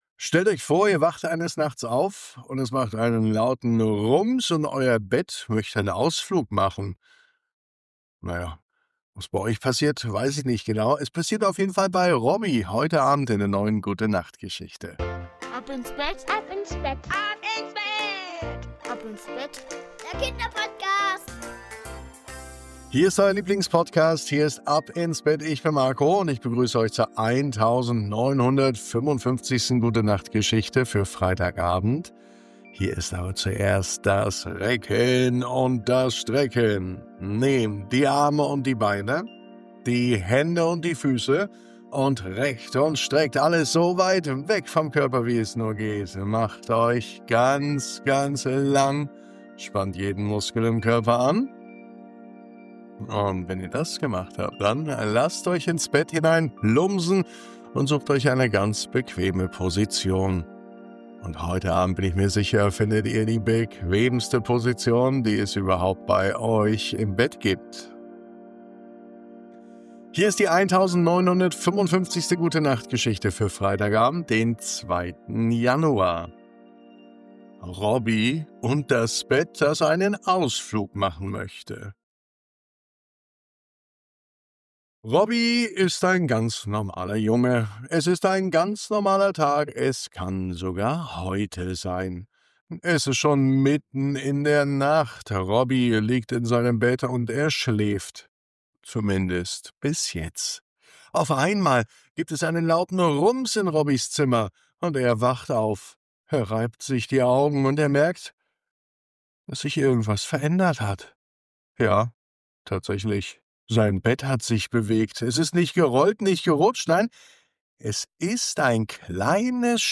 Eine sanfte Gute-Nacht-Geschichte über Vertrauen, Bewegung und das schöne Gefühl, am Ende wieder anzukommen.